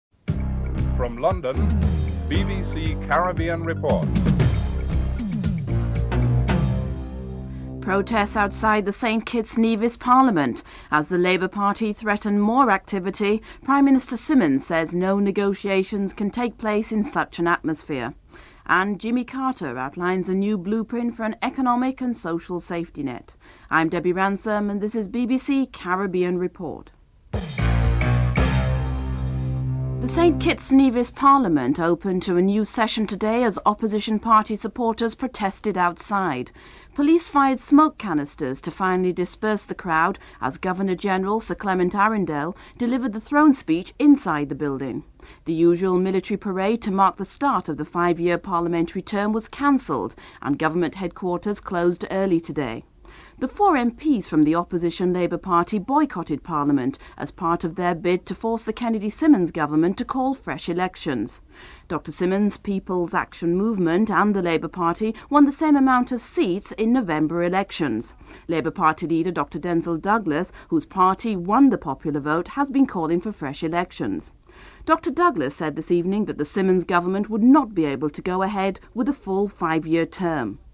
Former US President Jimmy Carter expresses his hope that conference to be held in Guyana would change the way multi-national co-oerpations do business with countries in the South.
Theme musiic (14:45-15:02)